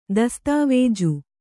♪ dastāvēju